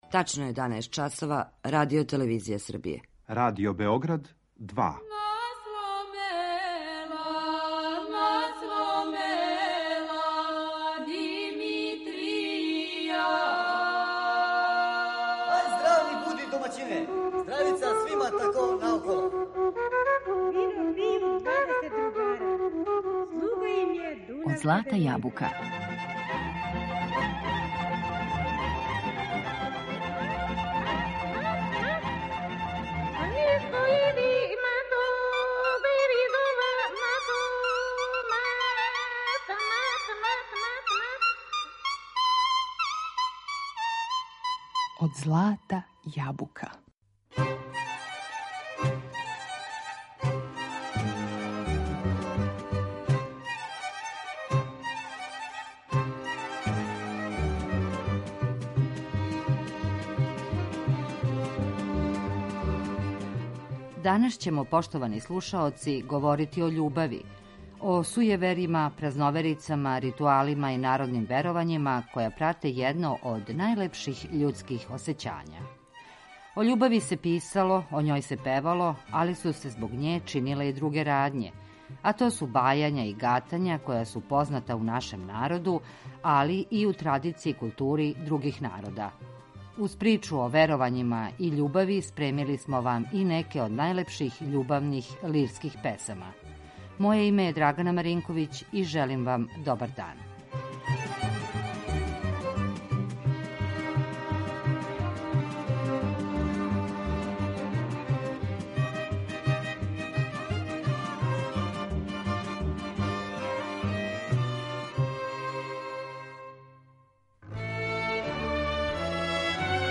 Уз причу о љубави и веровањима спремили смо и неке од најлепших љубавних лирских песама.